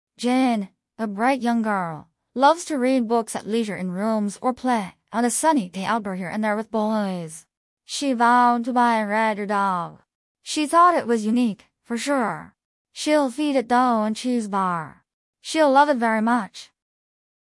Pangram_zh-CN-liaoning-XiaobeiNeural_Jane, a bright.mp3.mp3